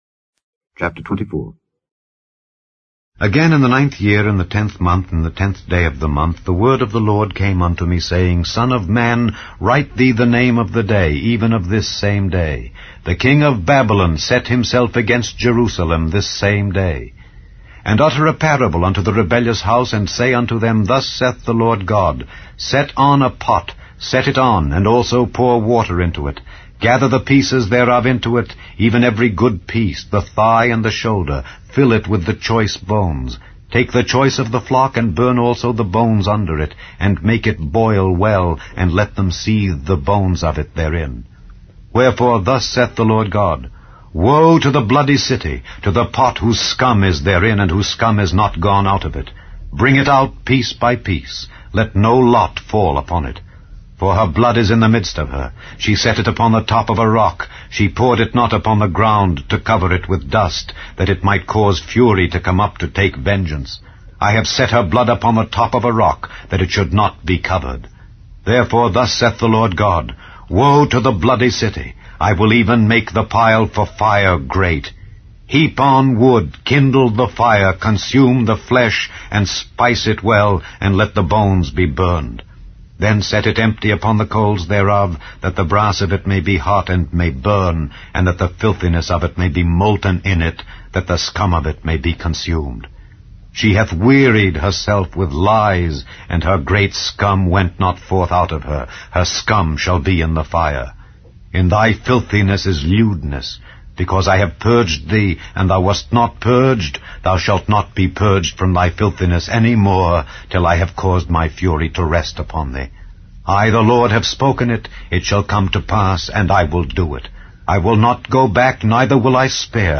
English Audio Bible - Ezekiel 4 in Kjv bible version